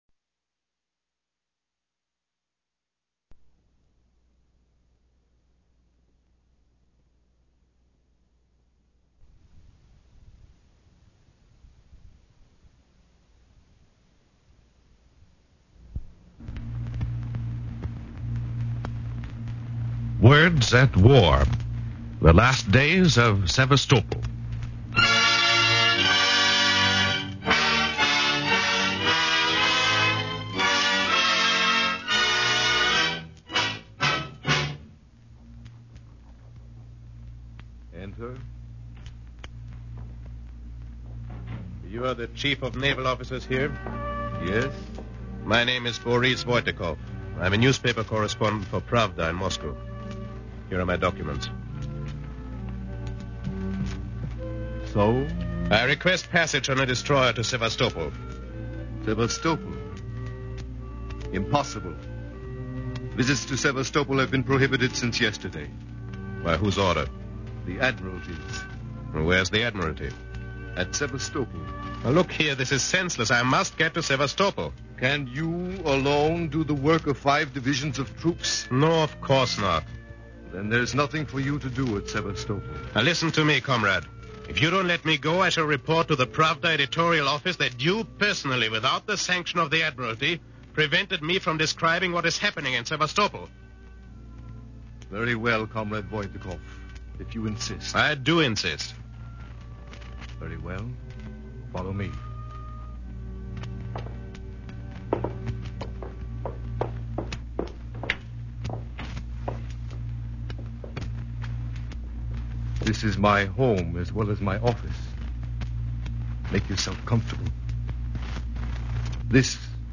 A very well done dramatization of the siege of this valiant Russian city by the Nazis, and its brave defenders. An announcement is made that this was the first book in history that was delivered to its publisher entirely by cable.